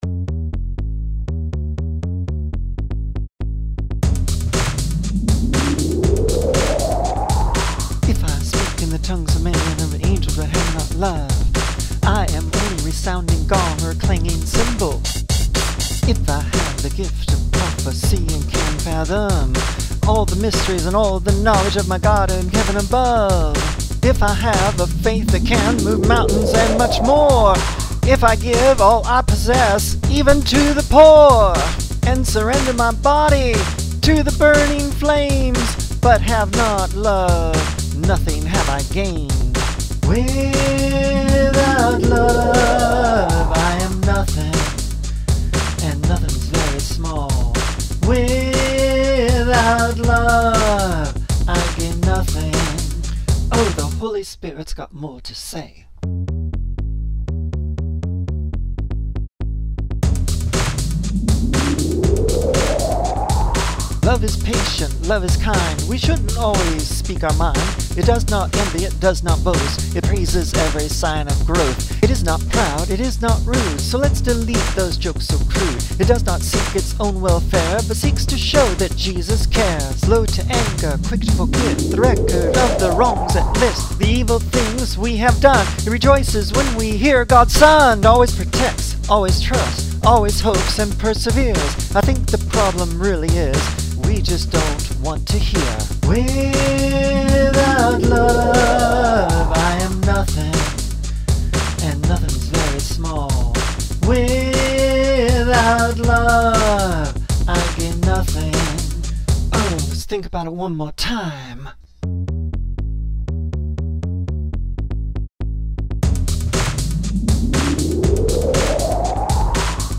extreme low tones and bass ... and these will not play